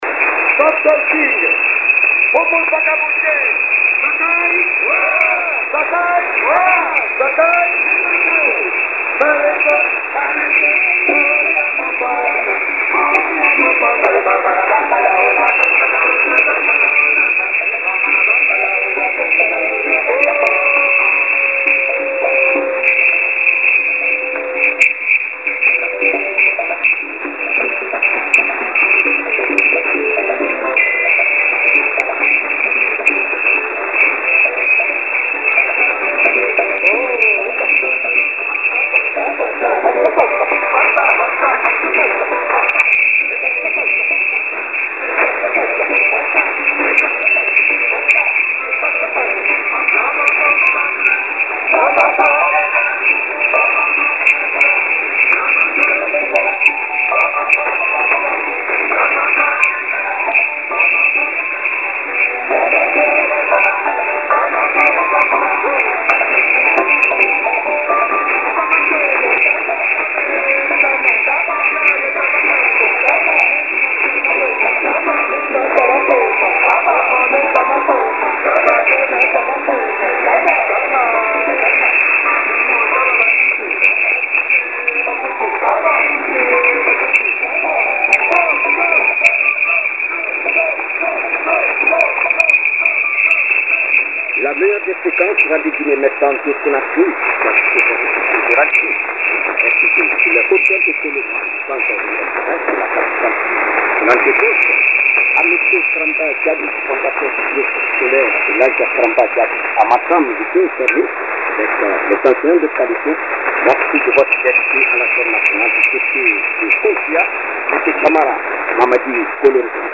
Voici un extrait effectué ce vendredi vers 2145 sur 9650; après la plage musicale, on entend le présentateur avec l’identification de la station